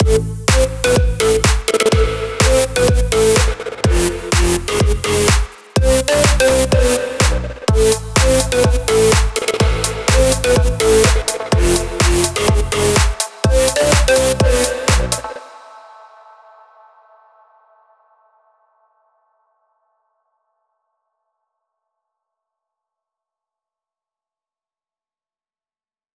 האוס.mp3 מוזיקה האוס { נראה לי שככה קוראים לזאנר}, תחקו לשנייה ה 55